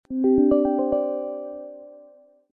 Windows 10 Notify Calendar Soundboard: Play Instant Sound Effect Button